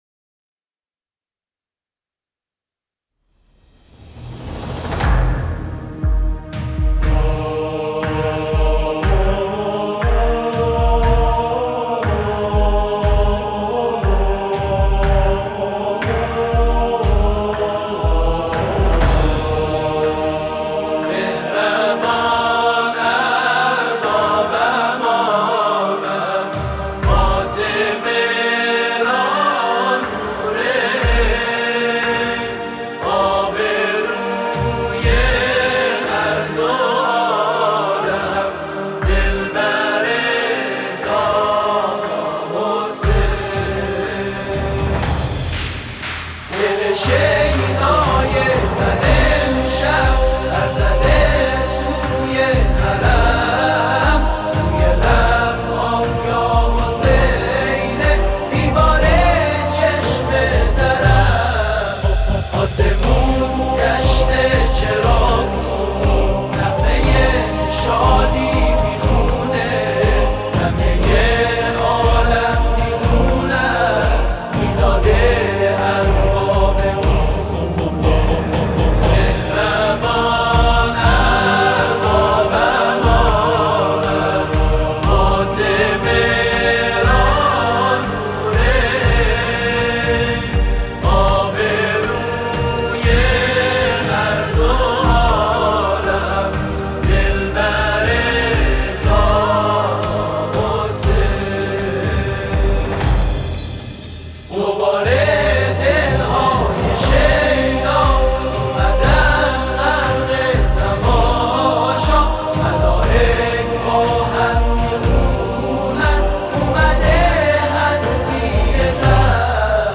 تواشیح و مدیحه‌سرایی